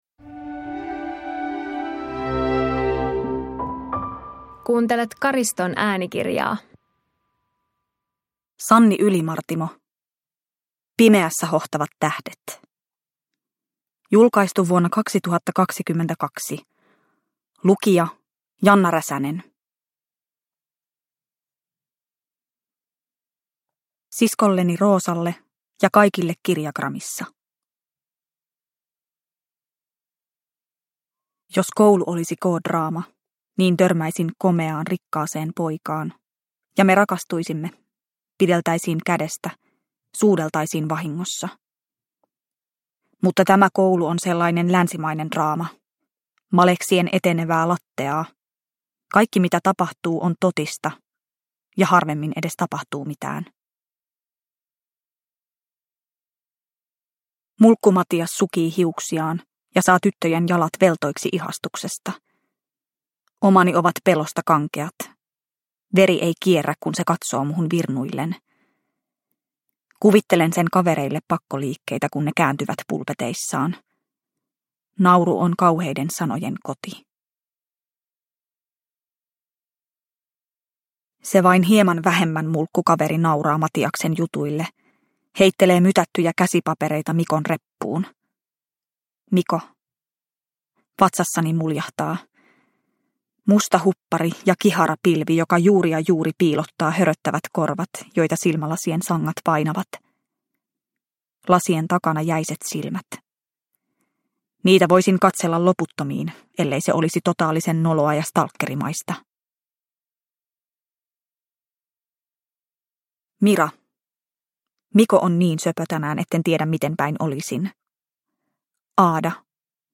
Pimeässä hohtavat tähdet – Ljudbok – Laddas ner